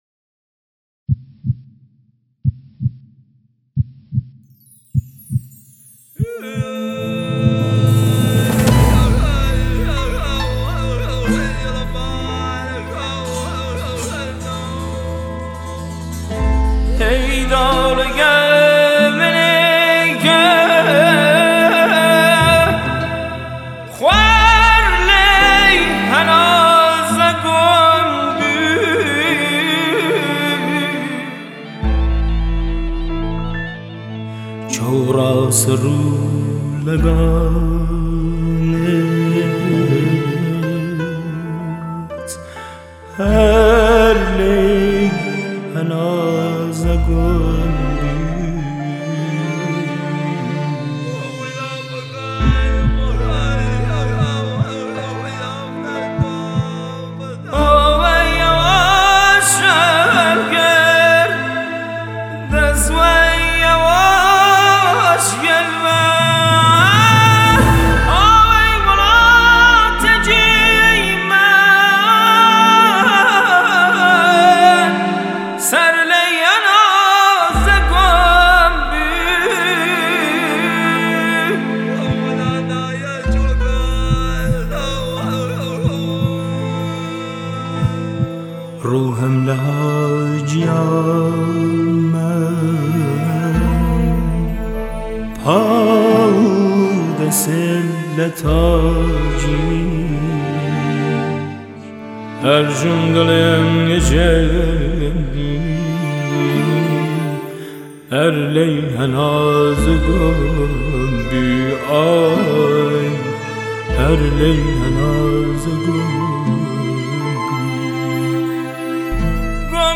موسیقی بومی و محلی